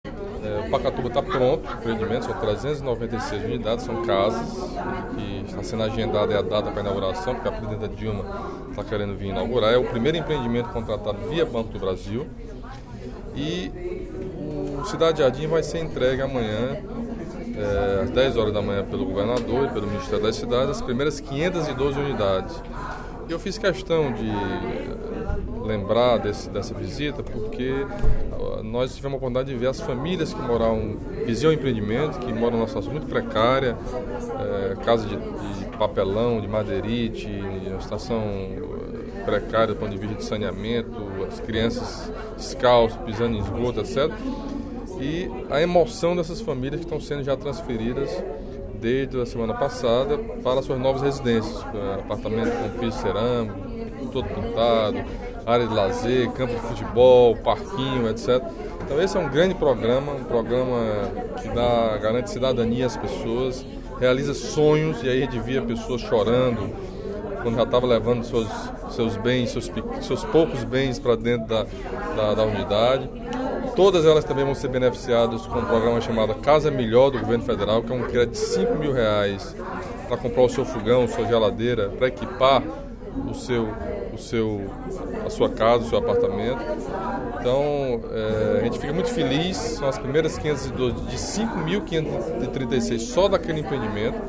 No primeiro expediente da sessão plenária desta terça-feira (25/02),  o deputado Camilo Santana (PT) informou que amanhã, pela manhã, o governador Cid Gomes, juntamente com o Ministério das Cidades, fará a entrega do primeiro lote de 512 unidades do residencial Cidade Jardim, no bairro José Walter.